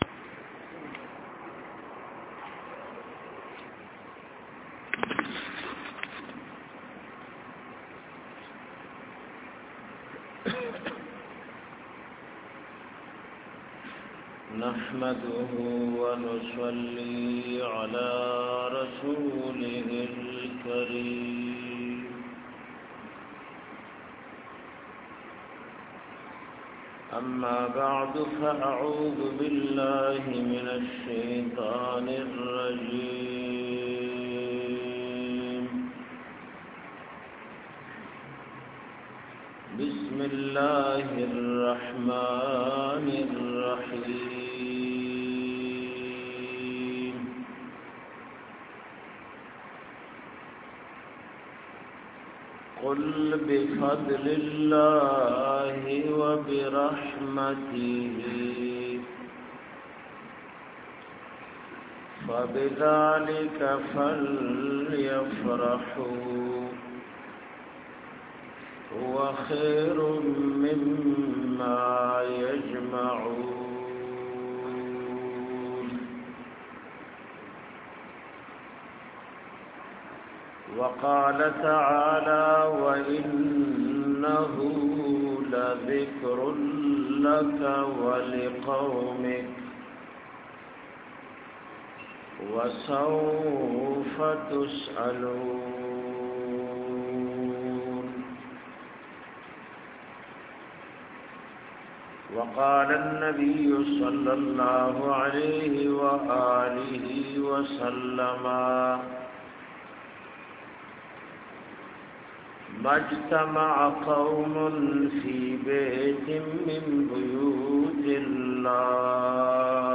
Bayan baselsela khatmul quran bamuqam bhai masjid jalbai.dt.13.5.17
Bayan-baselsela-khatmul-quran-bamuqam-bhai-masjid-jalbai.dt_.13.5.17.mp3